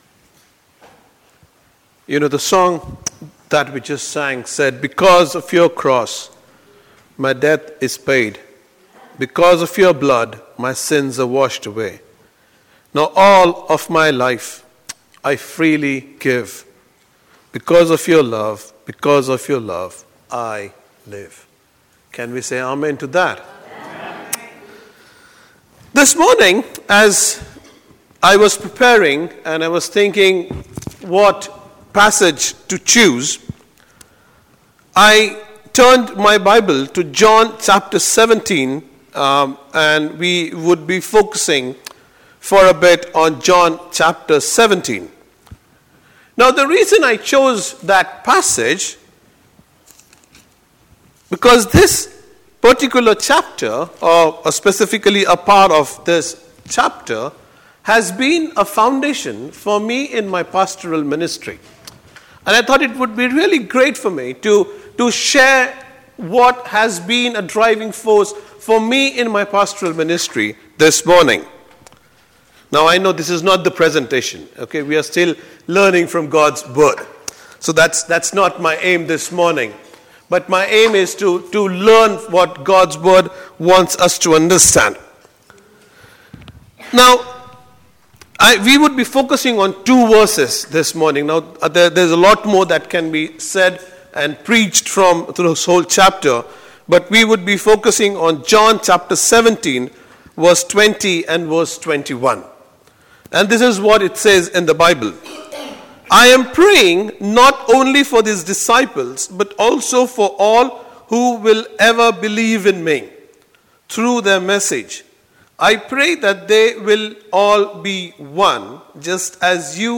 Sunday sermons – Countess Free Church Ely